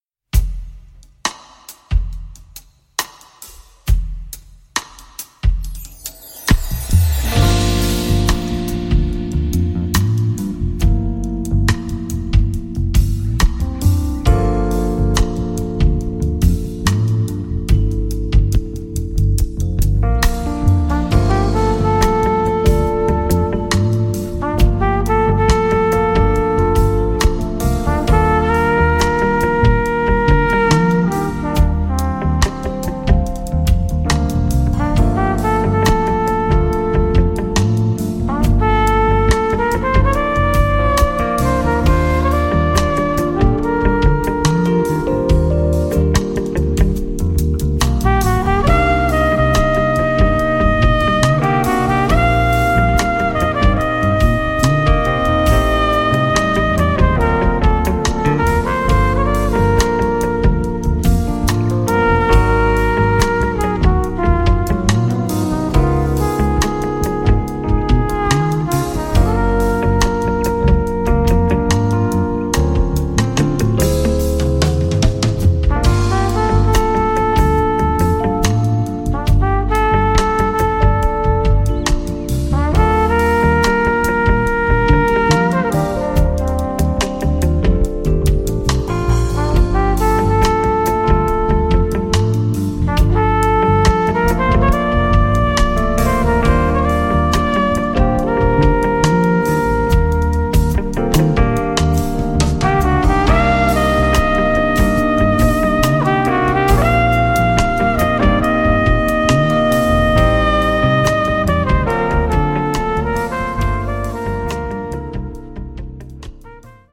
světoznámá dueta, swing, evergreeny, oldies